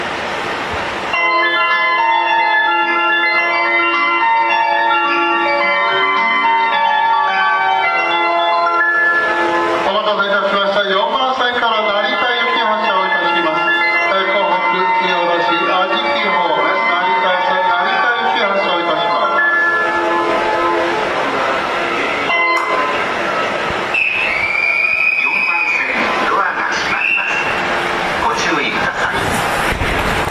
発車メロディをかき消してくれます。
ドアが開いた瞬間に発車メロディが鳴り出すのもこの駅ならではです。